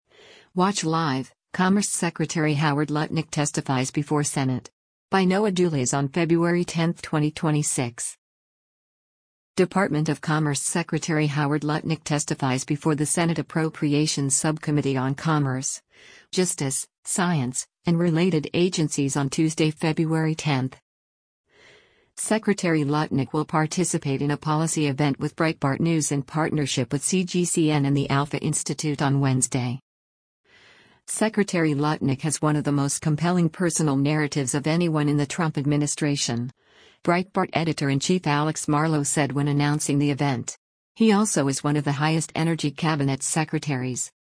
Department of Commerce Secretary Howard Lutnick testifies before the Senate Appropriations Subcommittee on Commerce, Justice, Science, and Related Agencies on Tuesday, February 10.